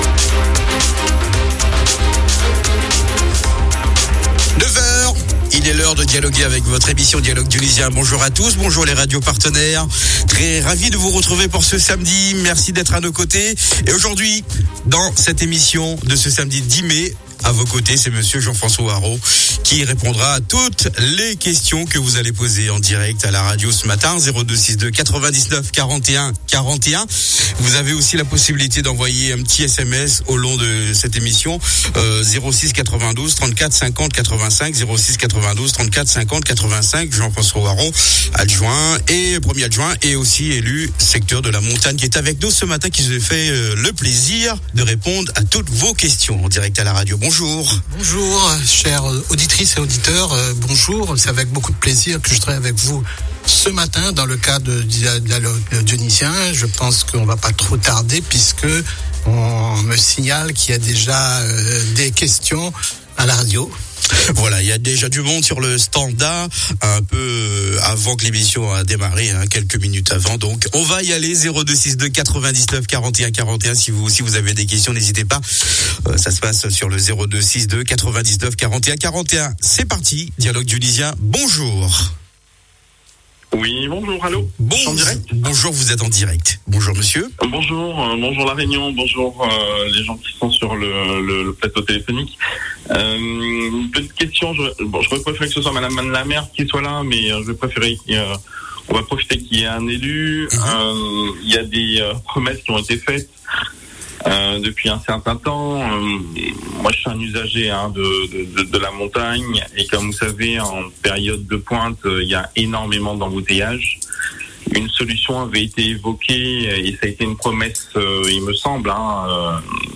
Votre émission Dialogue Dionysien, c'est tous les samedis à partir de 9h sur CAPITAL FM et les radios partenaires. 10 mai 2025
La maire de Saint-Denis, Mme Éricka Bareigts répond aux questions des auditeurs et revient sur les questions posées la semaine passée.